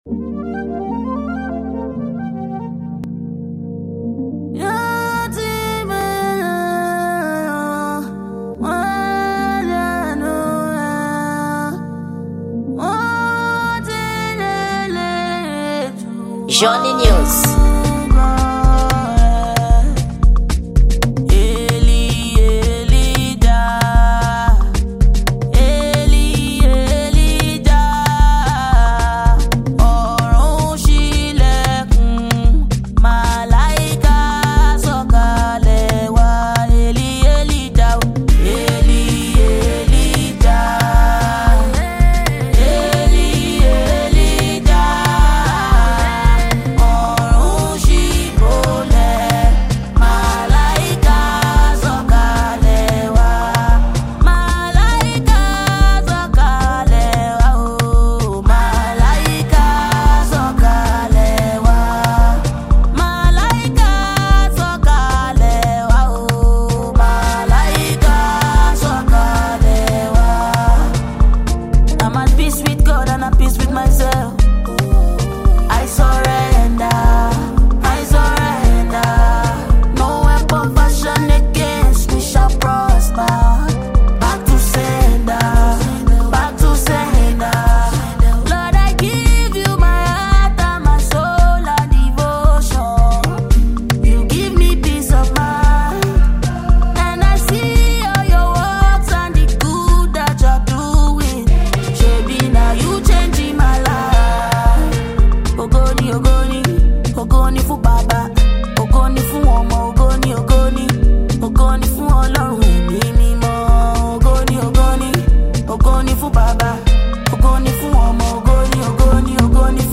Gênero: Amapiano